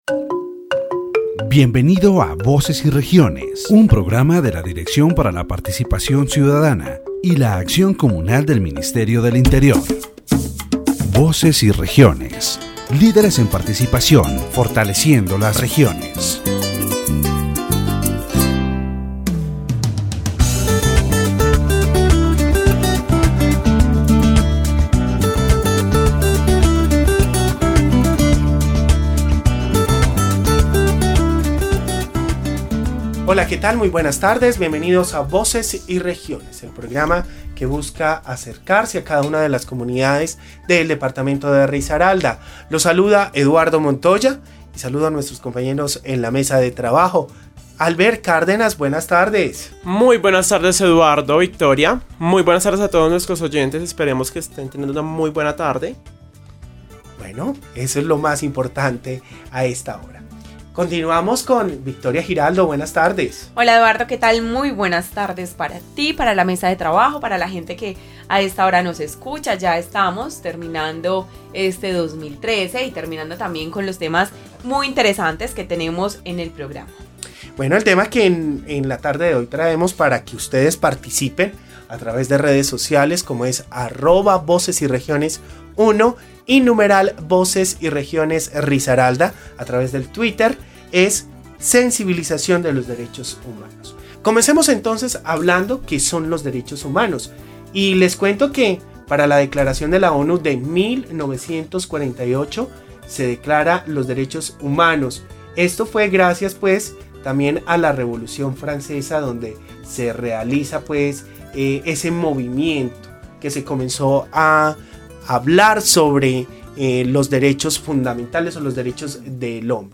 The radio program "Voices and Regions" of the Directorate for Citizen Participation and Community Action of the Ministry of the Interior, in its fourteenth episode, focuses on raising awareness about human rights.